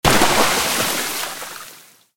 explosion_water2.ogg